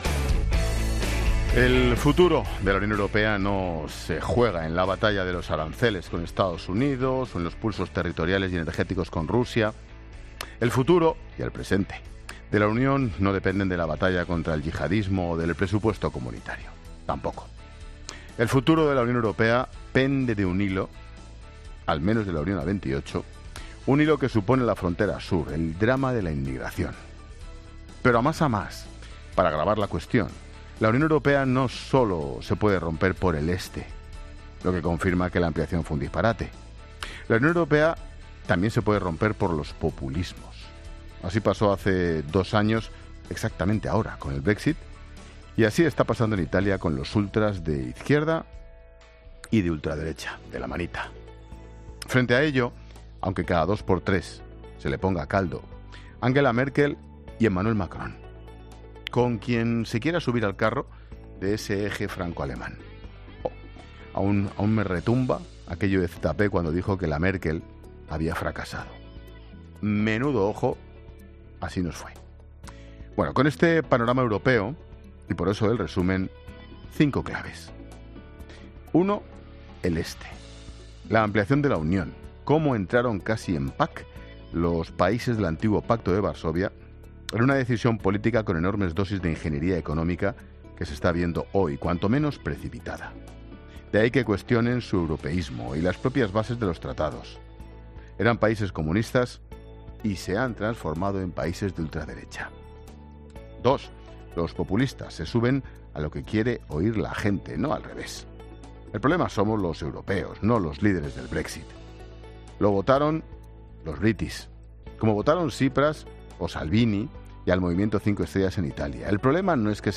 Monólogo de Expósito
El comentario de Ángel Expósito sobre la crisis que hay en Europa.